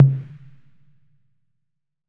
Tom_C2.wav